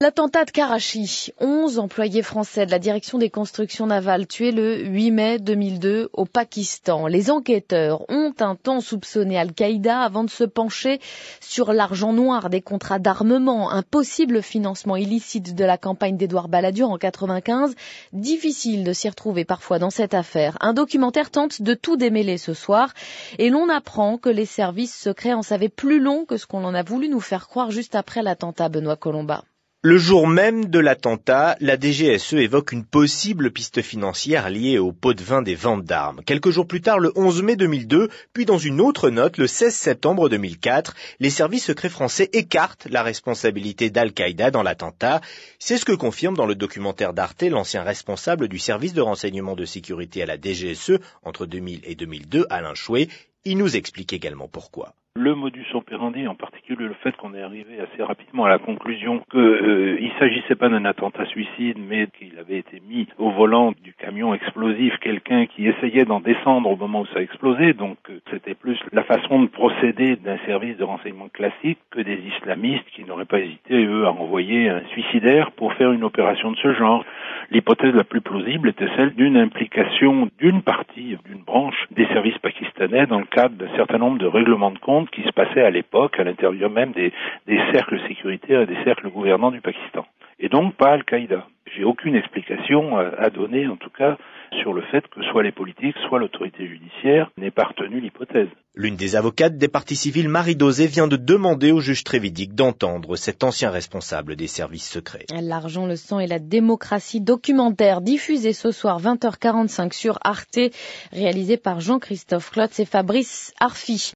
Diffusé sur France Inter le 15 octobre 2013